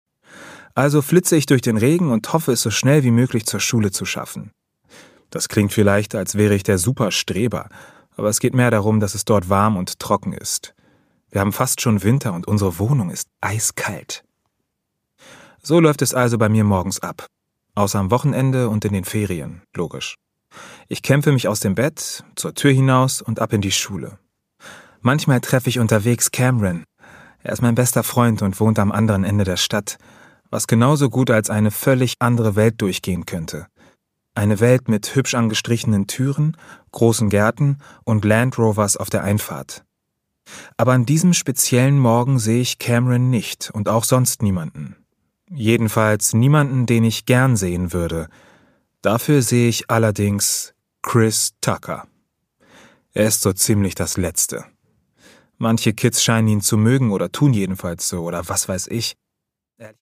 Produkttyp: Hörbuch-Download
Gelesen von: Benito Bause
Benito Bause, u. a. bekannt durch den erfolgreiche TV-Serie Doppelhaushälfte, erzählt warm und authentisch.